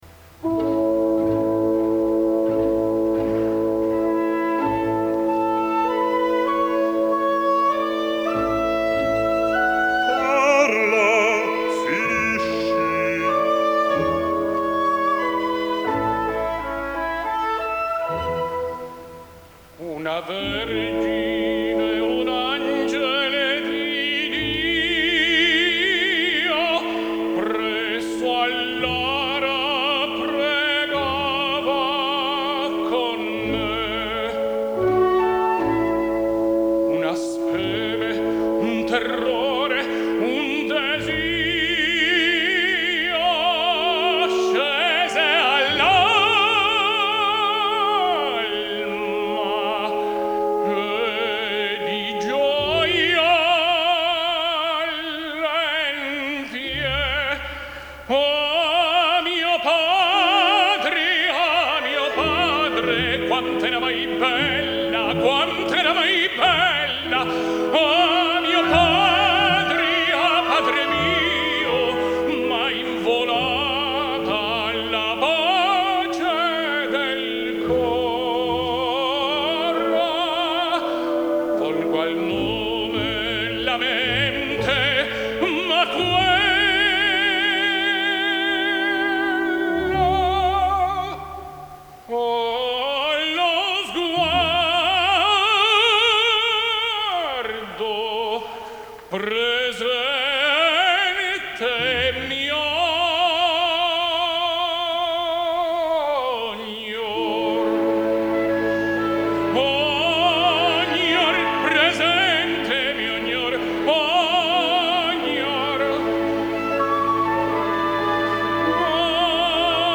Alfredo Kraus sings La favorite: